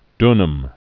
(dnəm)